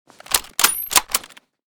sv98_unjam.ogg.bak